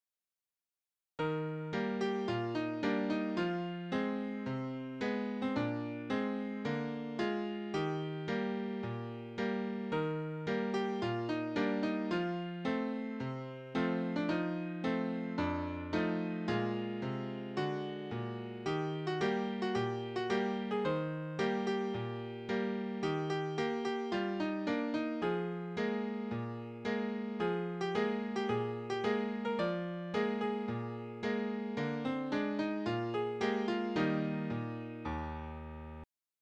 Vocal Solo